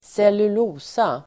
Ladda ner uttalet
cellulosa.mp3